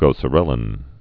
(gōsə-rĕlĭn)